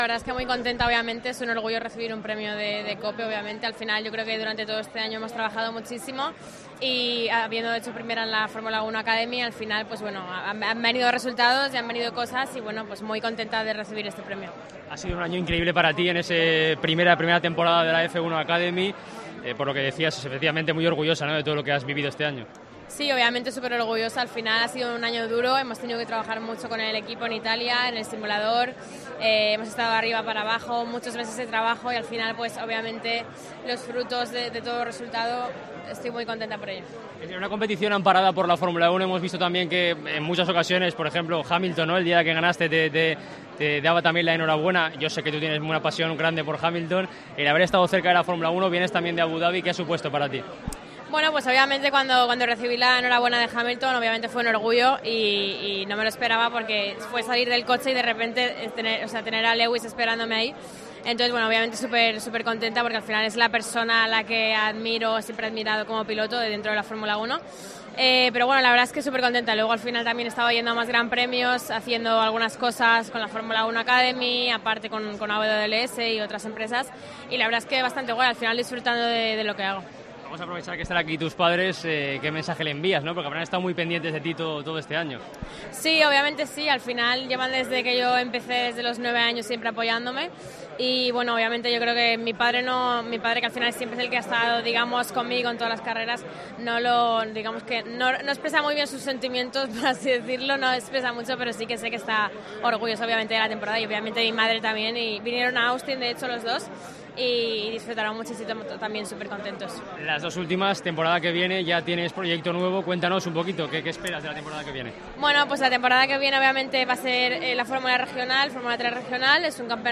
Entrevista Marta García en los XII Premios COPE Valencia